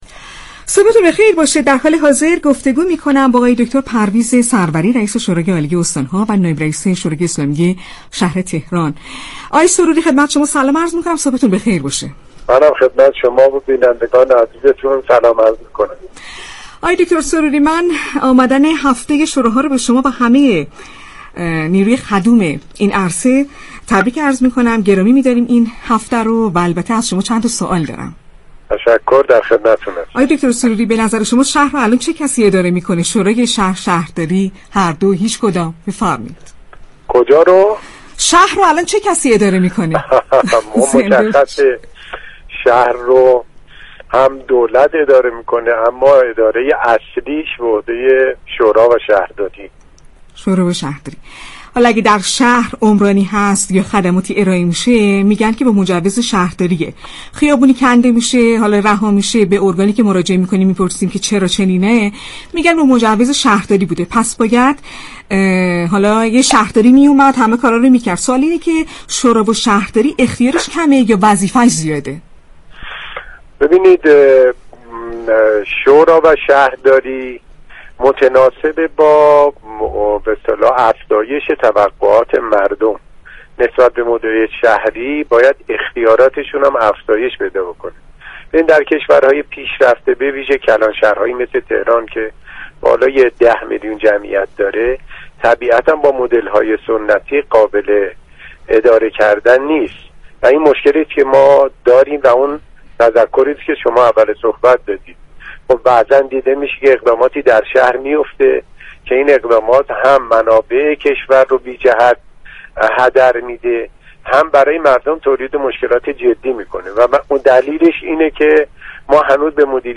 به گزارش پایگاه اطلاع رسانی رادیو تهران، پرویز سروری رئیس شورای عالی استان‌ها و نایب رئیس شورای اسلامی شهر تهران در گفت و گو با «شهر آفتاب» رادیو تهران اظهار داشت: اختیارات شوراها و شهرداری باید متناسب با توقعات مردم نسبت به مدیریت شهری افزایش پیدا كند.